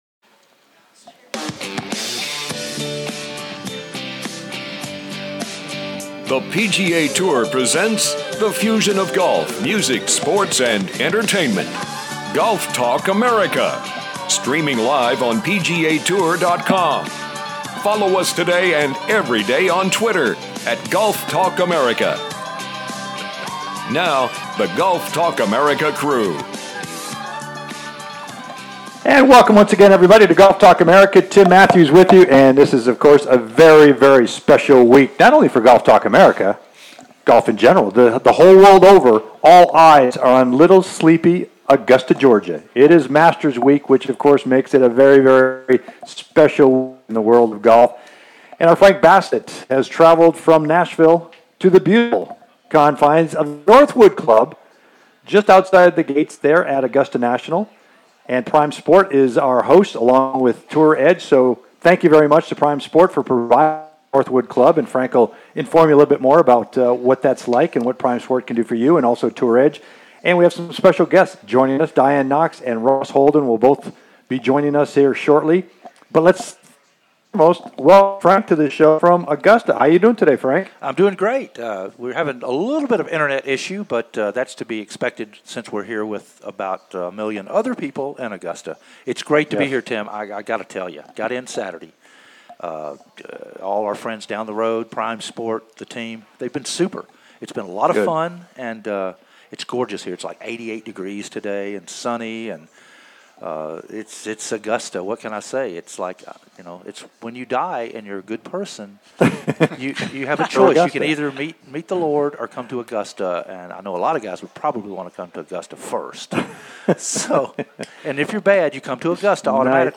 Monday "LIVE" From The Masters